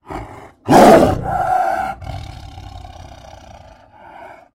Звуки саблезубого тигра
Звук: устрашающий рев саблезубого тигра